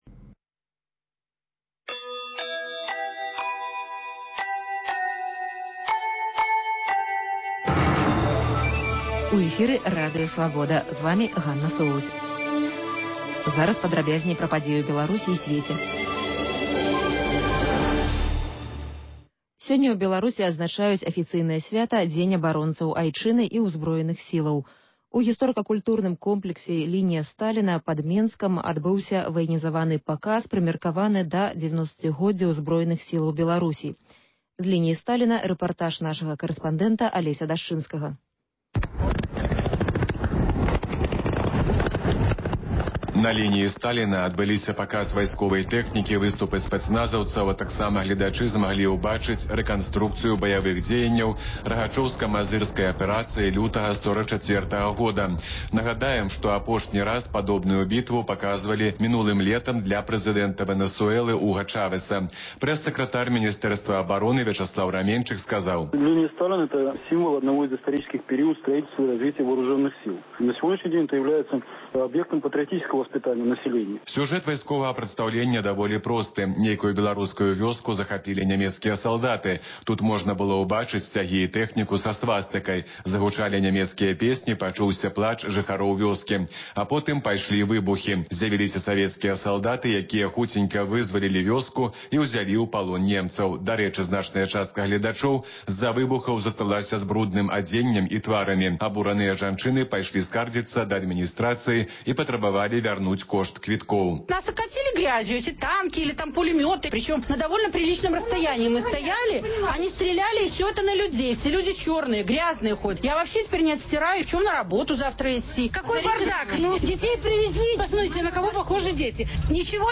Паведамленьні нашых карэспандэнтаў, званкі слухачоў, апытаньні на вуліцах беларускіх гарадоў і мястэчак.